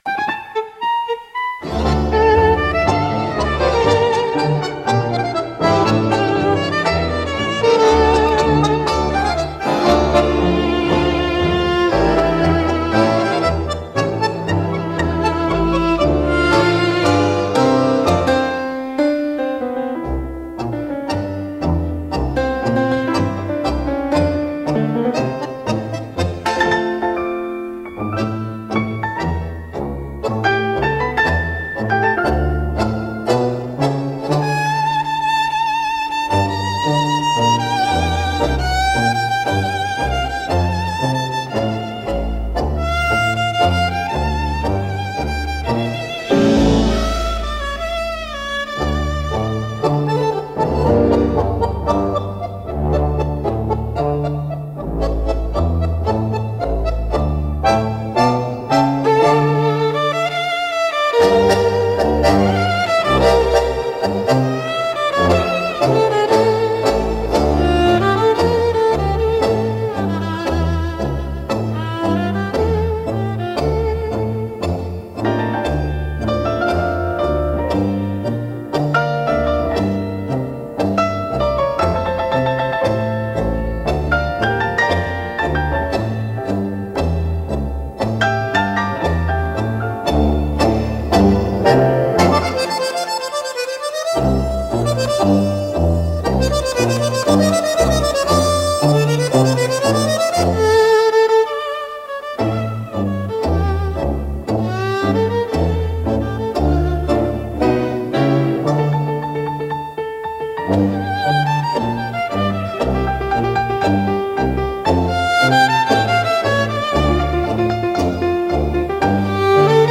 música e arranjo: IA) instrumental 7